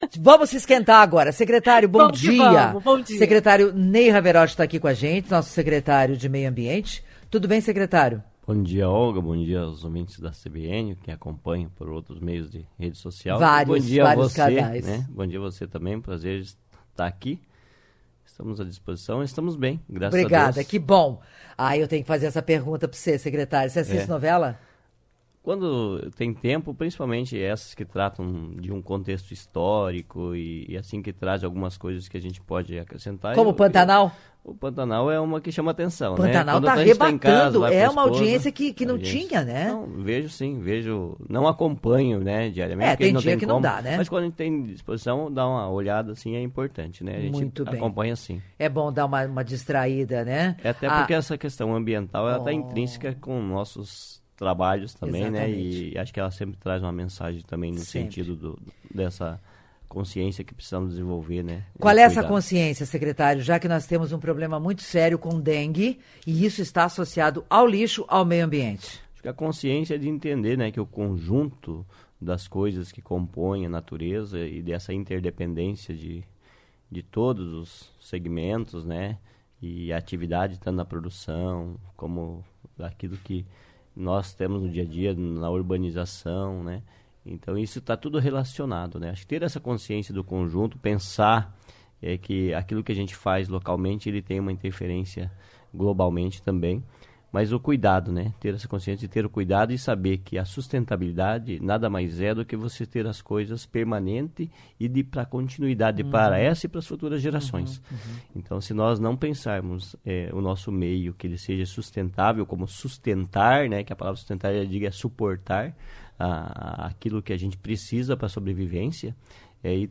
Em entrevista com Olga Bongiovanni, no Revista CBN deste sábado (02), o secretário de Meio Ambiente de Cascavel, Nei Havereth, falou sobre a importância da reciclagem.